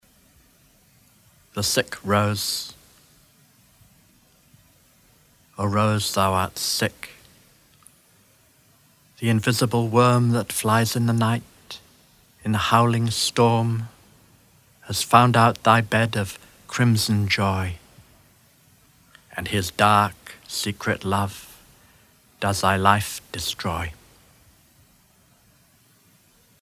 The Sick Rose by William Blake read by Adrian Mitchell